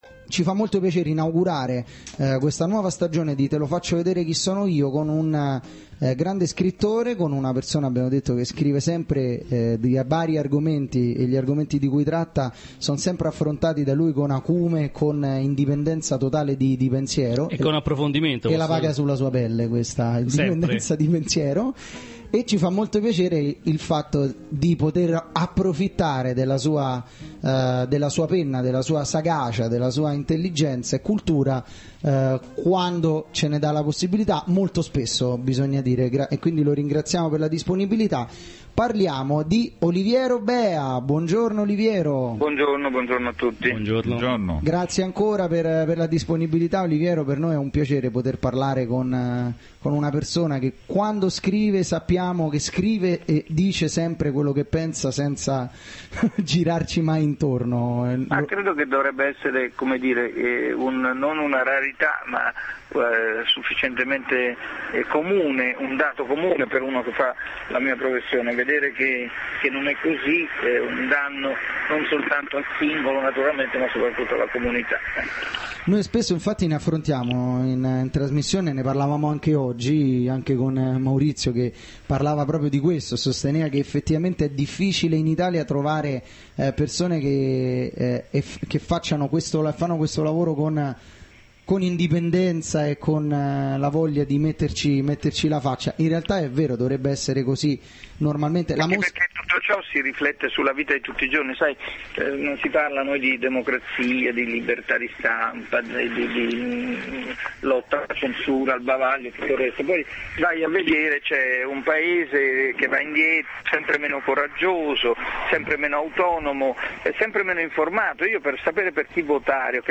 Intervento telefonico Oliviero Beha del 01/09/2010